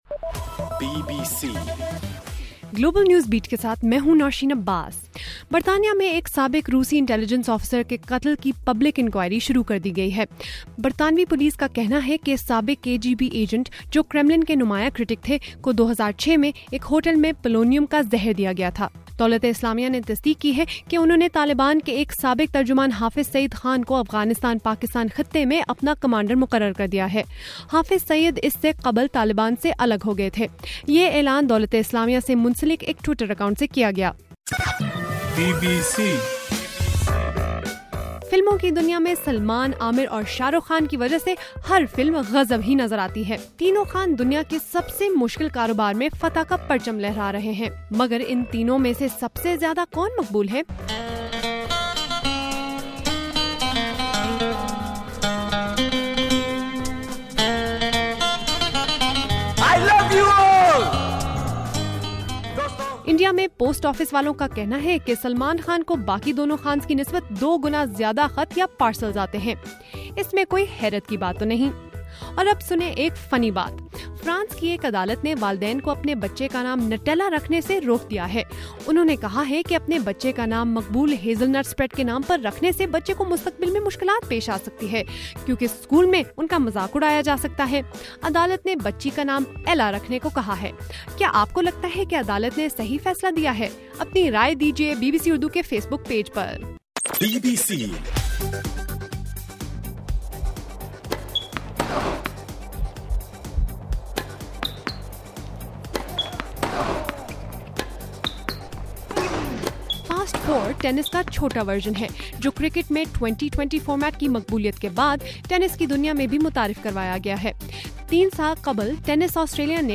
جنوری 27: رات 8 بجے کا گلوبل نیوز بیٹ بُلیٹن